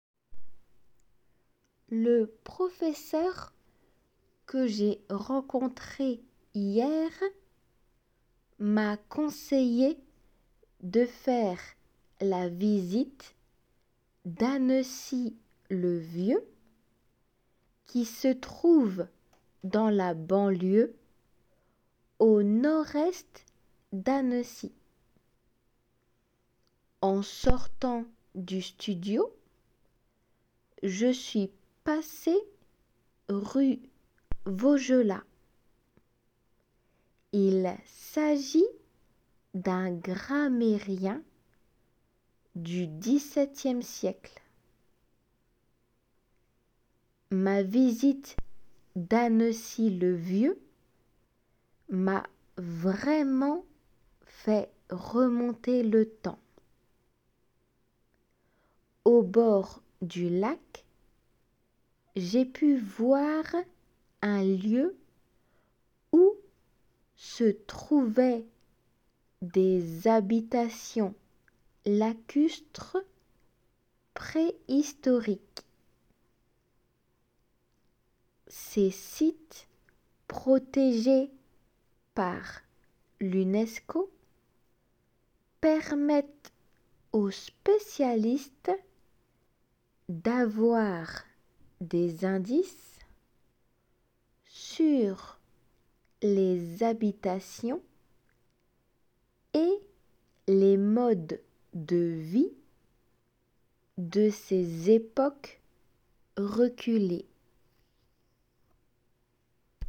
仏検2級レベルの　聞き取り、　デイクテ練習用
des habitations リエゾン